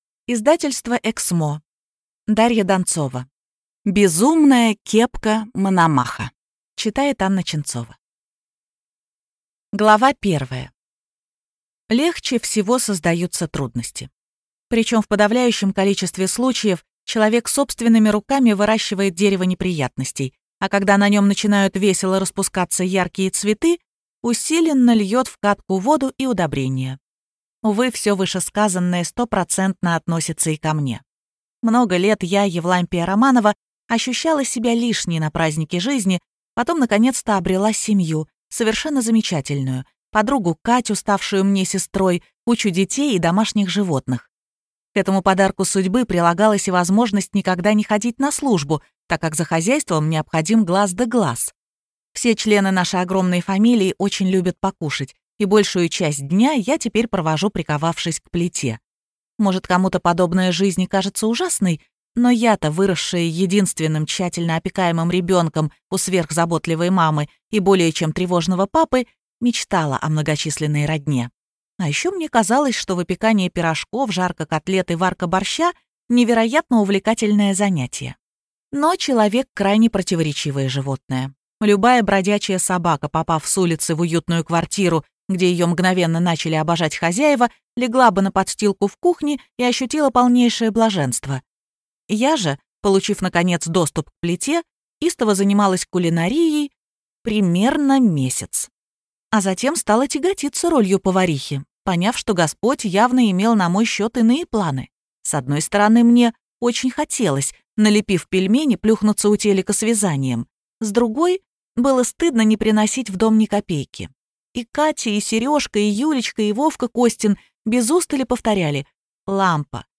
Аудиокнига Безумная кепка Мономаха | Библиотека аудиокниг